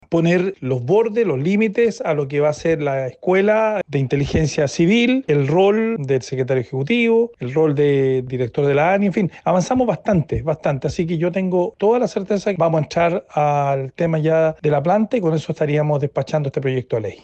A su vez, el diputado y presidente de Amarillos, Andrés Jouannet, manifestó que ya se han establecido los bordes para la nueva ANIC, y el rol del secretario ejecutivo y del director del organismo.